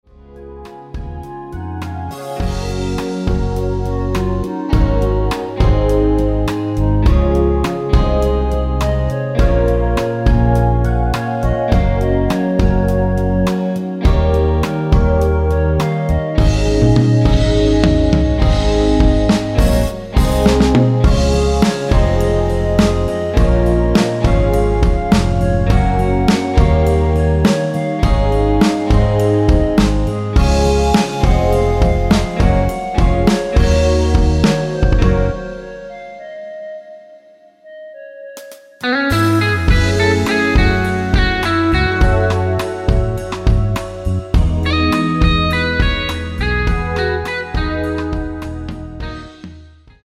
원키 멜로디 포함된 MR입니다.(미리듣기 확인)
Db
앞부분30초, 뒷부분30초씩 편집해서 올려 드리고 있습니다.